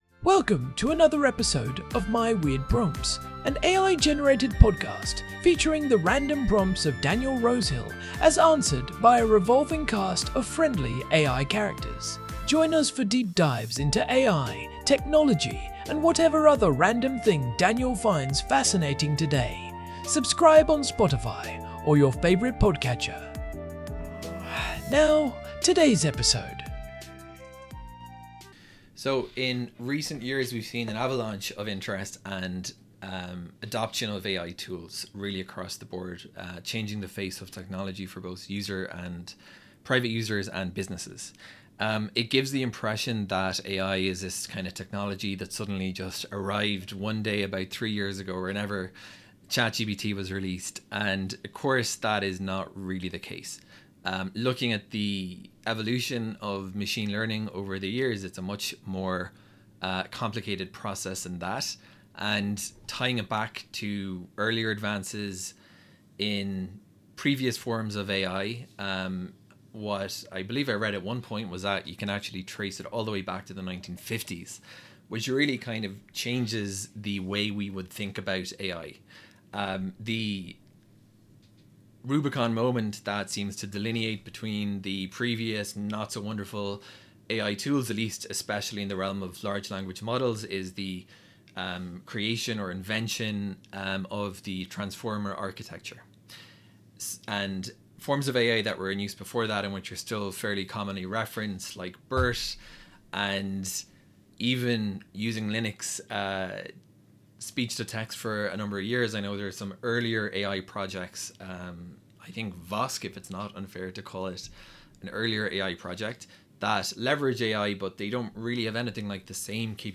AI-Generated Content: This podcast is created using AI personas.
TTS Engine chatterbox-tts
Hosts Herman and Corn are AI personalities.